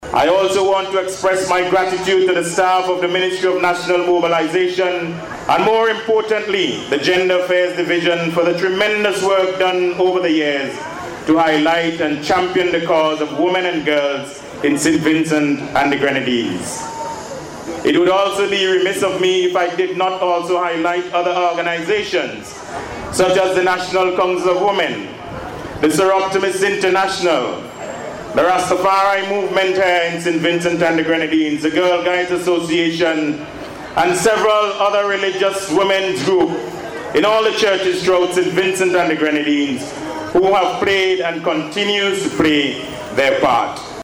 Here in St. Vincent and the Grenadines, the Department of Gender Affairs held a March and Rally in Kingstown last week Friday, to observe the day.
In his Address, Minister of Gender Affairs Frederick Stephenson applauded women of St. Vincent and the Grenadines who have made significant contributions to national development and have been agents of change in their communities.